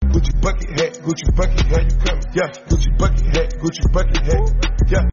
AY 67 meme sound effect
Gucci-Bucket-Hat-meme-sound-effect.mp3